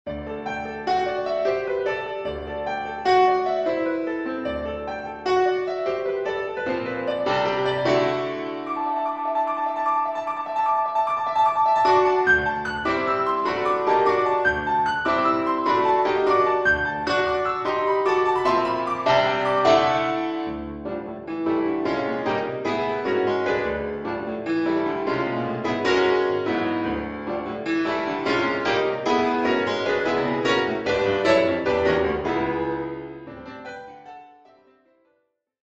Piano Solo